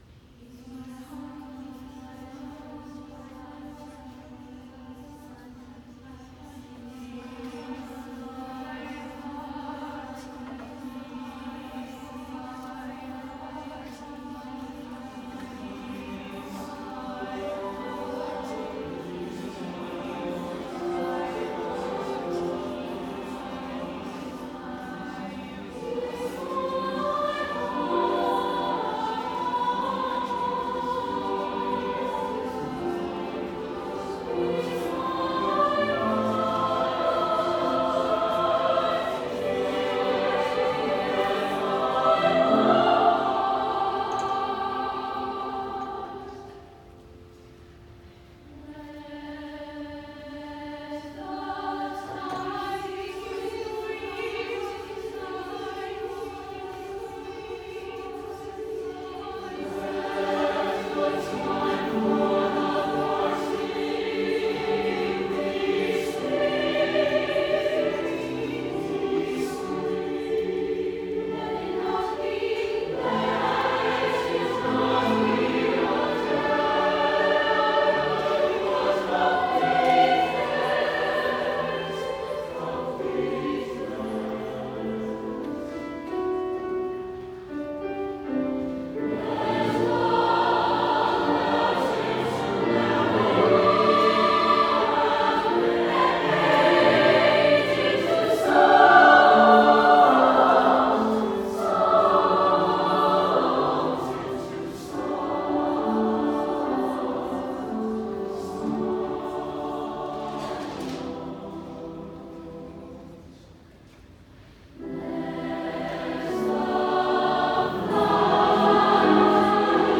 SATB Chorus, SA Chorus, & Piano